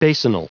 Prononciation du mot basinal en anglais (fichier audio)
Prononciation du mot : basinal